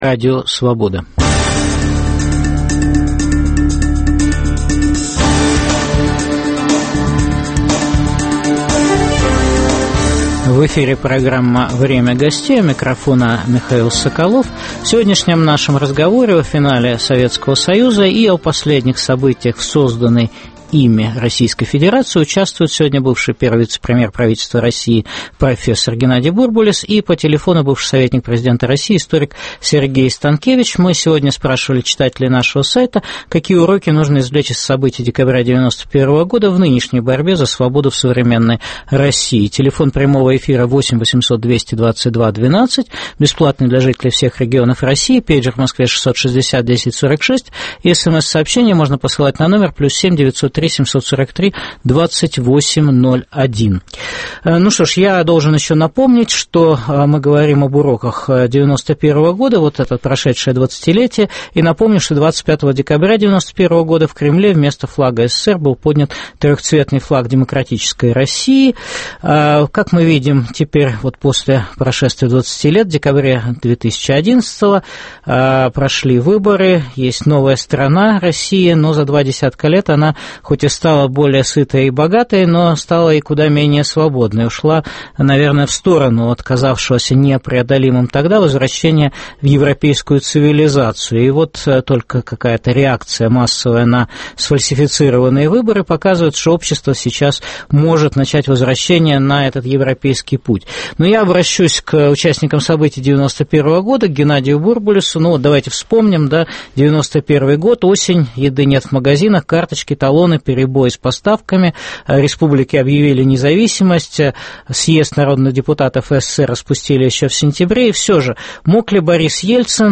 Какие уроки нужно извлечь из событий декабря 1991 в борьбе за свободу в современной России? В программе в дискуссии о финале СССР и о последних событиях в созданной ими Российской Федерации участвуют бывший первый вице-премьер правительства РФ профессор Геннадий Бурбулис и бывший советник президента РФ историк Сергей Станкевич.